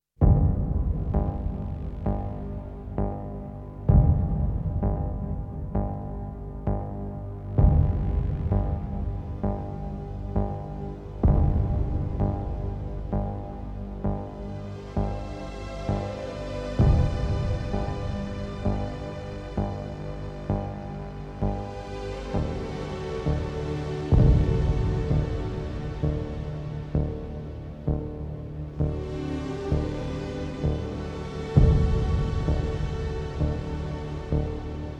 Жанр: Поп музыка / Электроника / Фолк